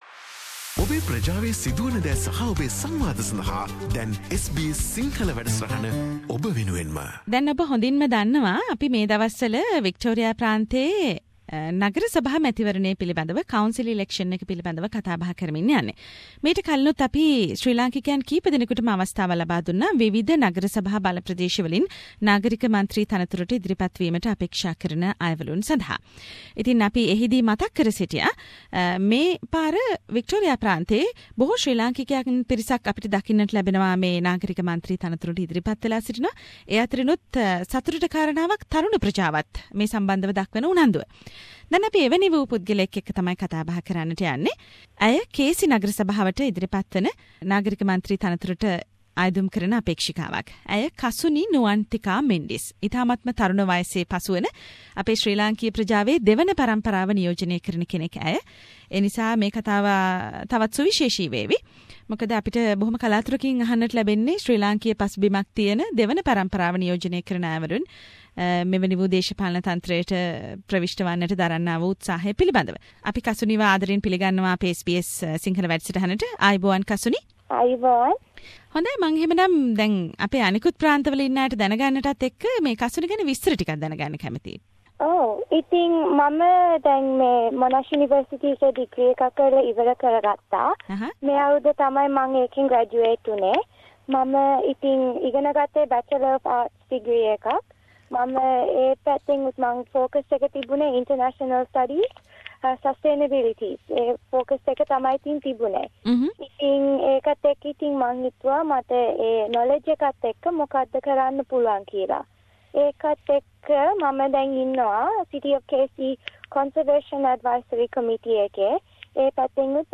SBS Sinhalese program interviewed another council election candidacy